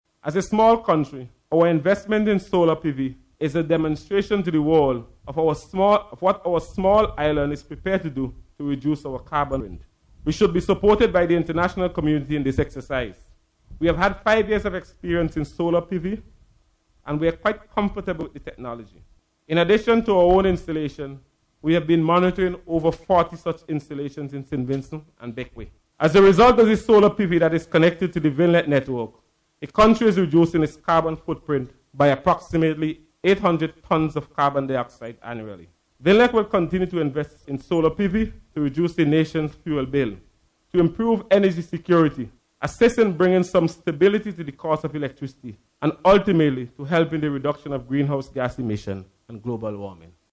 during his address at the launch of CARICOM Energy Week.